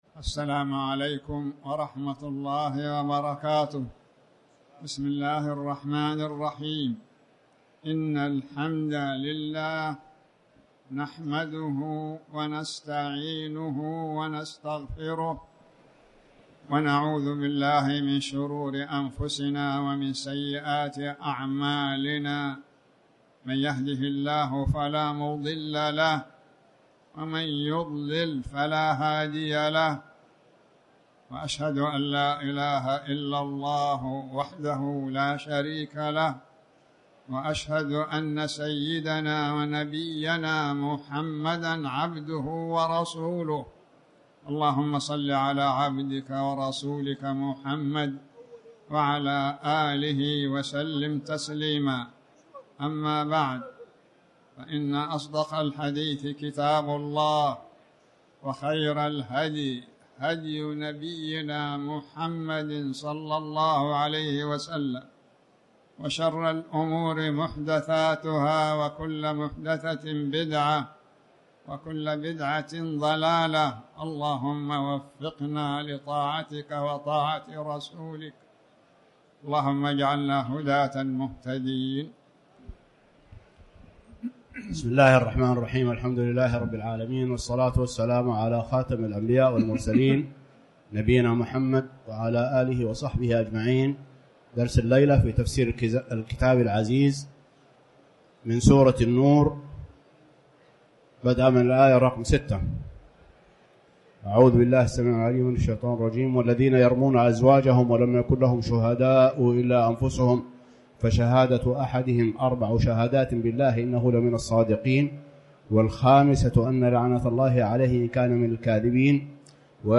تاريخ النشر ٤ ربيع الأول ١٤٤٠ هـ المكان: المسجد الحرام الشيخ